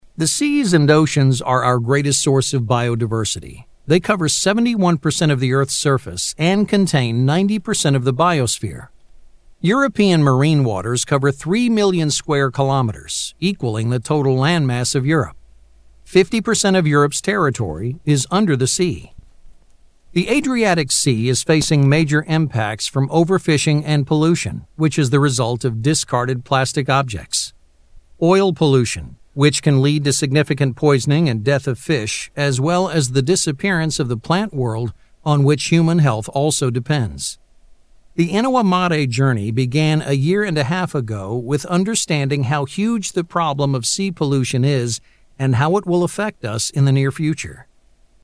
男英15 美式英语男声 宣传片广告 干音 大气浑厚磁性|沉稳|娓娓道来